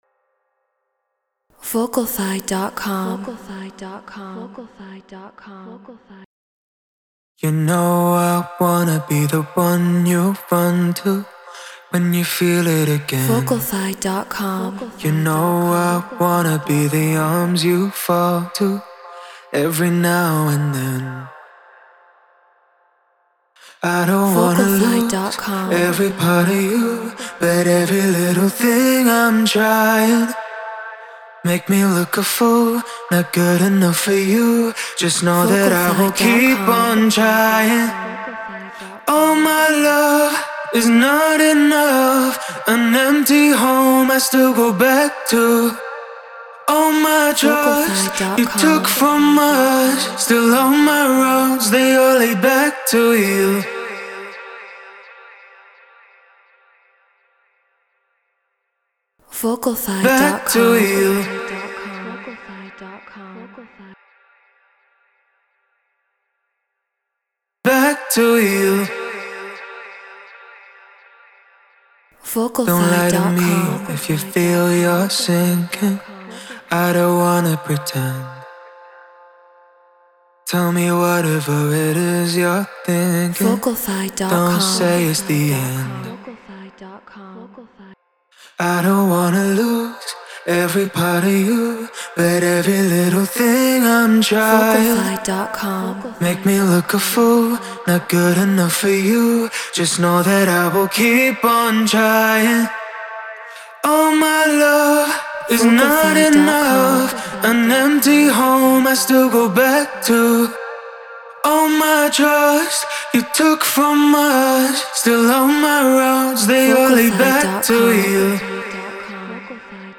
Progressive House 126 BPM A#maj
RØDE NT1-A Apollo Solo FL Studio Treated Room